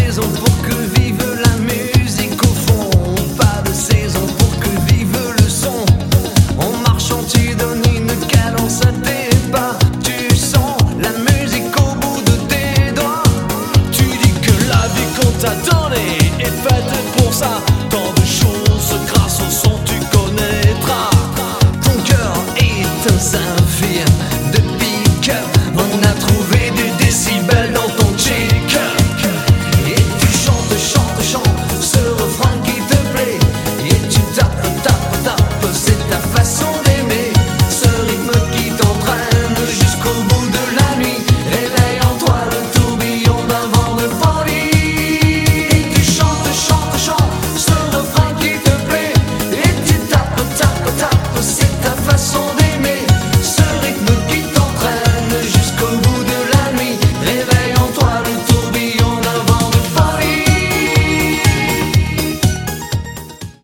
• Качество: 165, Stereo
мужской голос
Electronic
disco